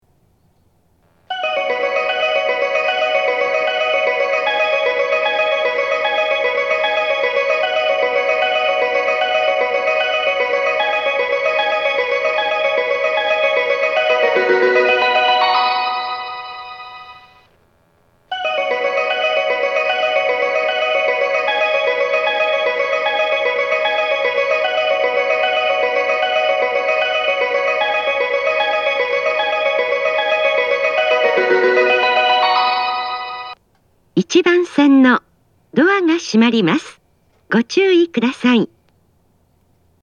スピーカーが上下兼用なので、交換のある列車の場合、放送が被りやすいです。
発車メロディー
1.9コーラスです!交換がなくても曲が長いので鳴りにくいです。